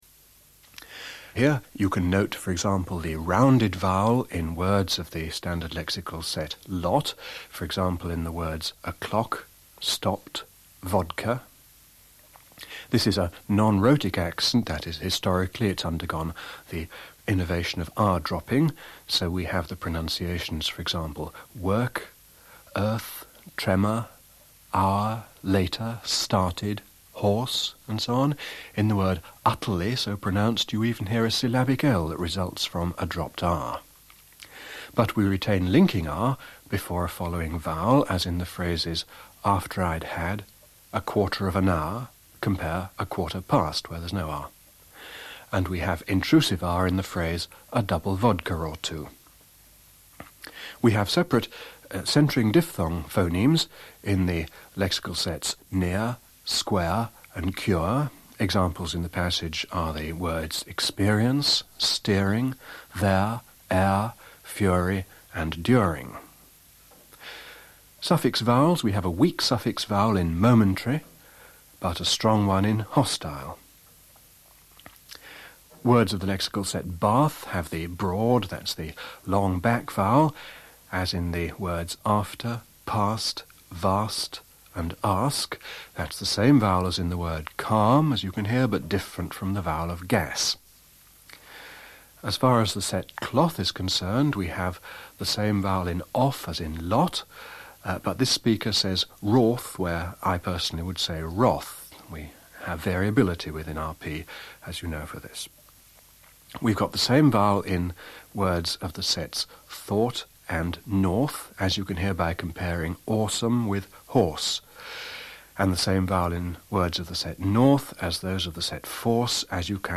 1-RP-comments.mp3